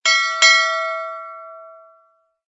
SZ_DD_shipbell.ogg